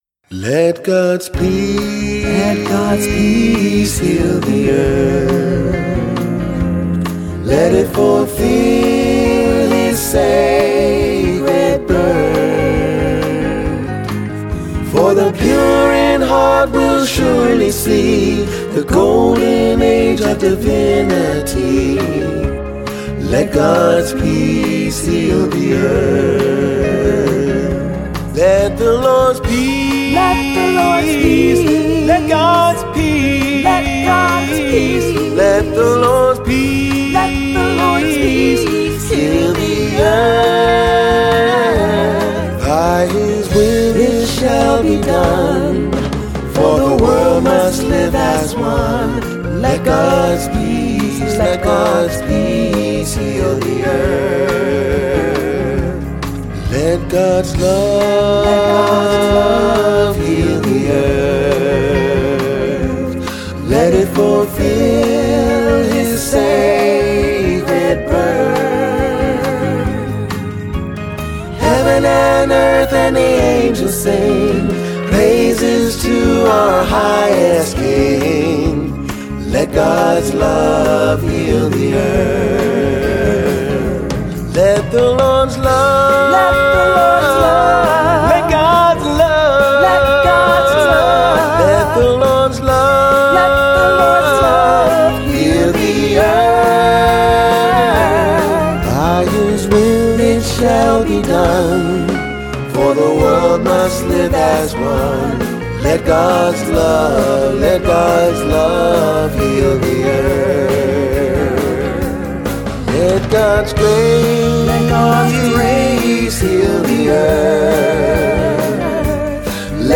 1. Devotional Songs
Major (Shankarabharanam / Bilawal)
8 Beat / Keherwa / Adi
Medium Fast